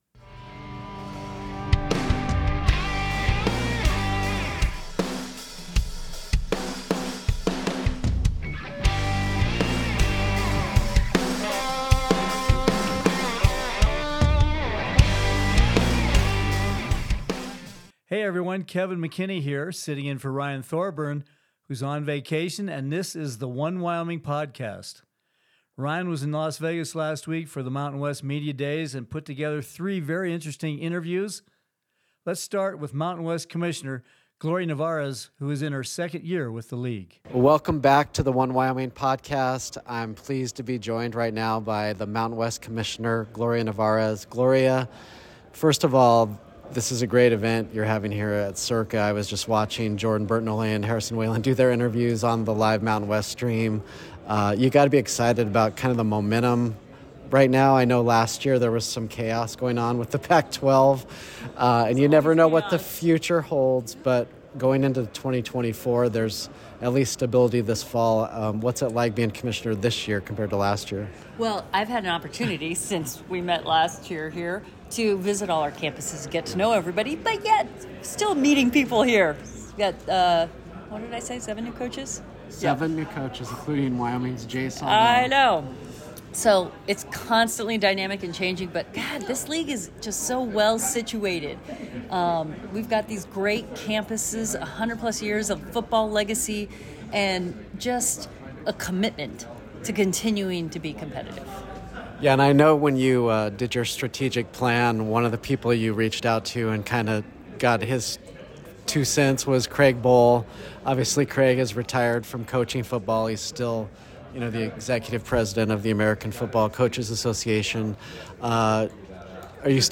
One_Wyoming_Podcast_at_MW_Media_Days.mp3